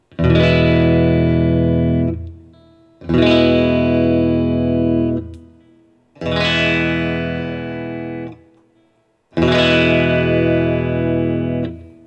All recordings in this section were recorded with an Olympus LS-10.
For each recording, I cycle through all four of the possible pickup combinations, those being (in order): neck pickup, both pickups (in phase), both pickups (out of phase), bridge pickup.
Open E Chords